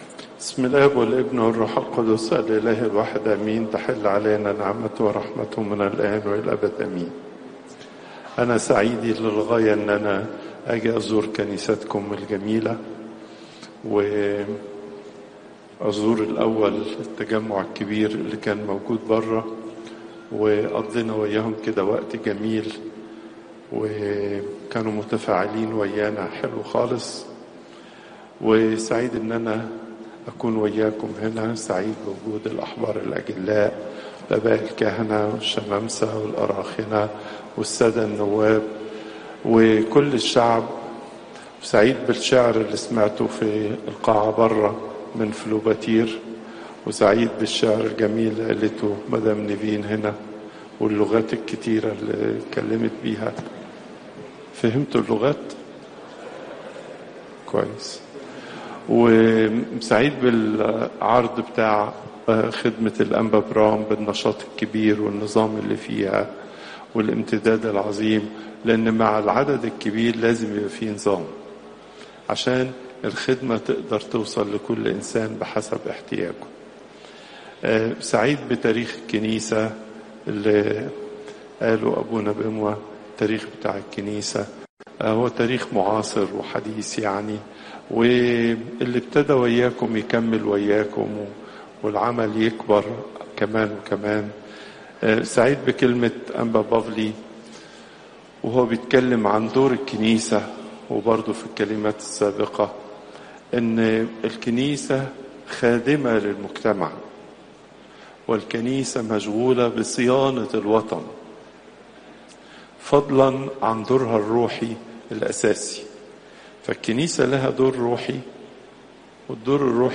Popup Player تحميل الصوت البابا تواضروس الثانى الأربعاء، 25 يونيو 2025 39:36 المحاضرة الأسبوعية لقداسة البابا تواضروس الثاني الزيارات: 224